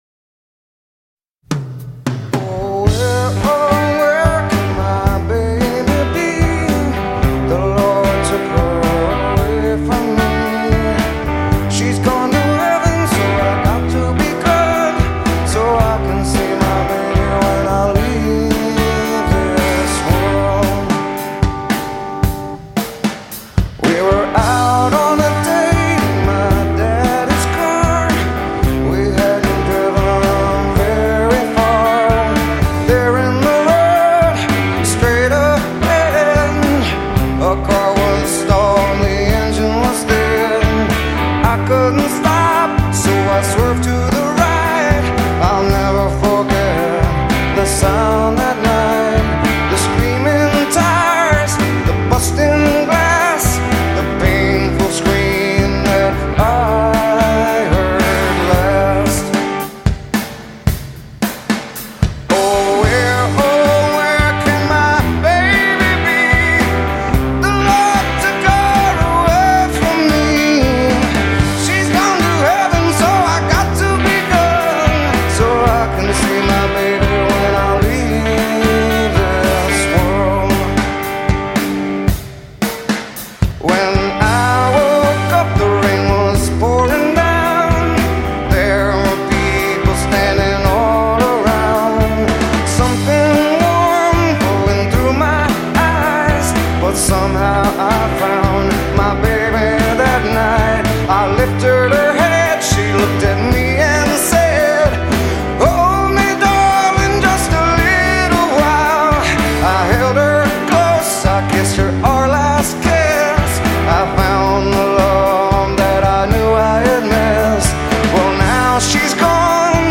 Standard Tuning - 4/4 Time
Chord Progression: G, Em, C, D